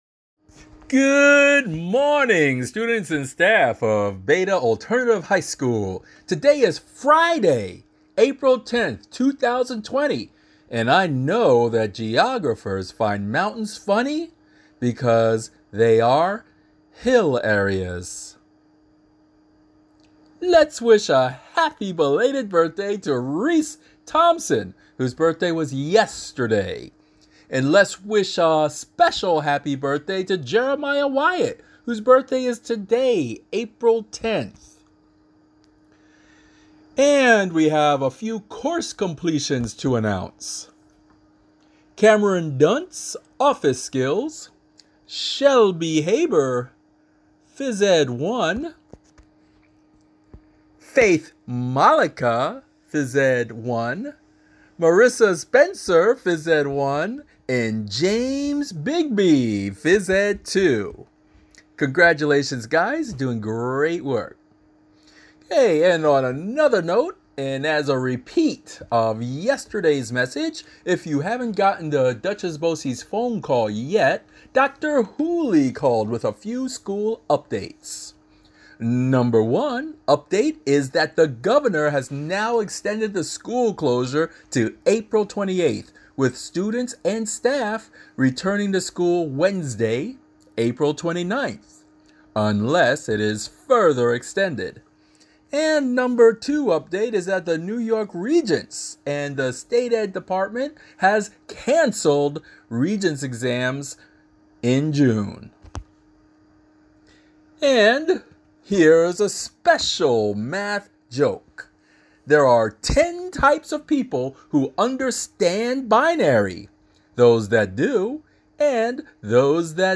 BETA Alternative High School - April 10 2020 Morning Announcement [MP3 File]